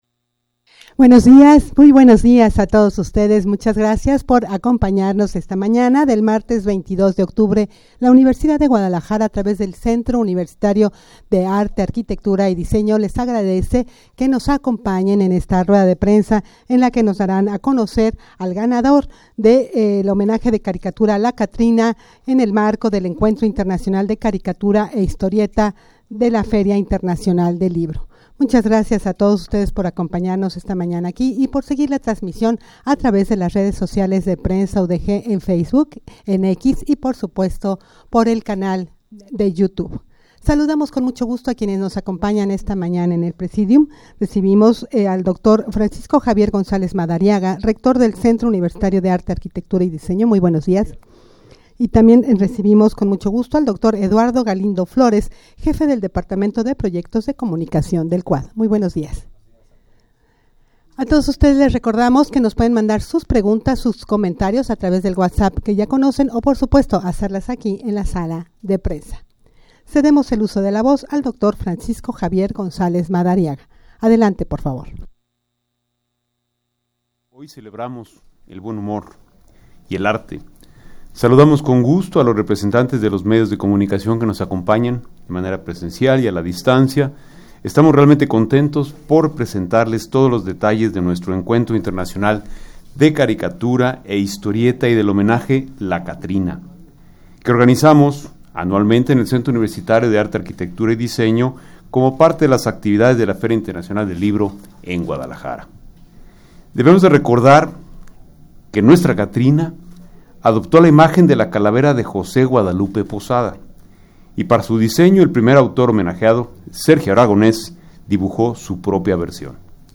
Audio de la Rueda de Prensa
rueda-de-prensa-para-dar-a-conocer-informacion-sobre-el-ganador-del-homenaje-de-caricatura-la-catrina.mp3